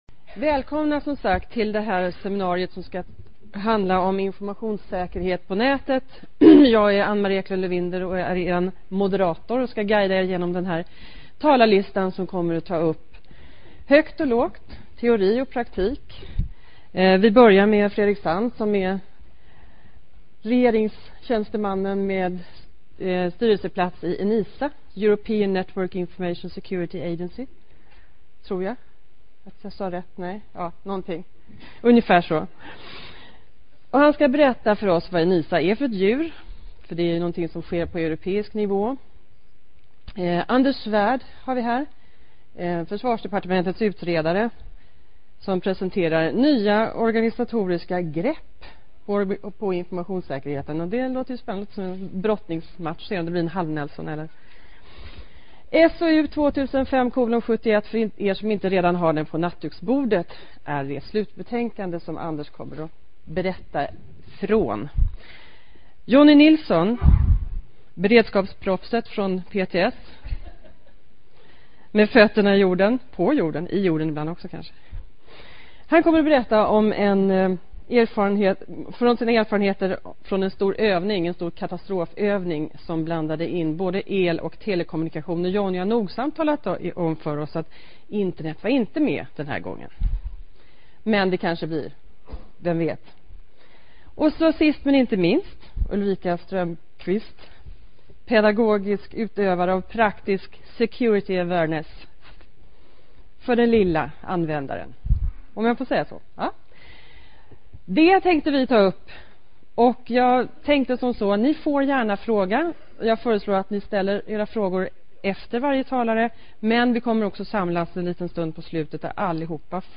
Internetdagarna 2005 - Informationss�kerhet p� n�tet
Under seminariet diskuterar vi strategier p� alla niv�er, b�de nationellt och internationellt.